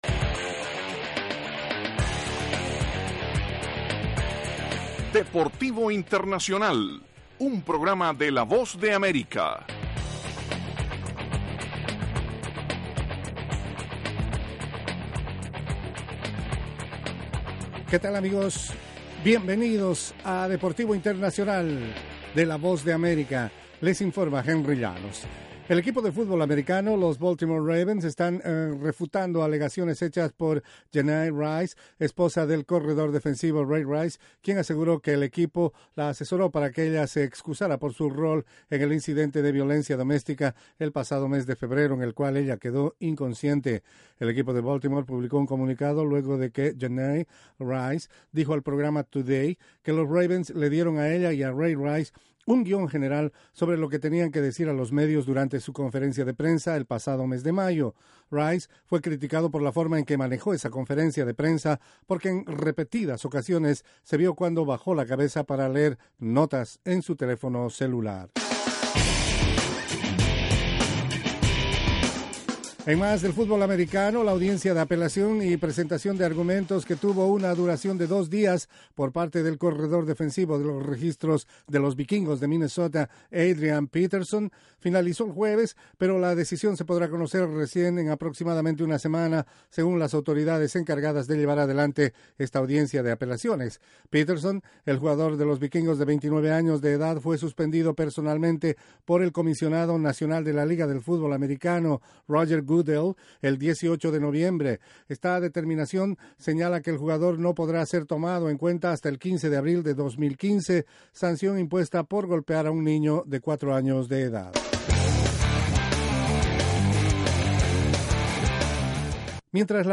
presenta las noticias más relevantes del mundo deportivo desde los estudios de la Voz de América.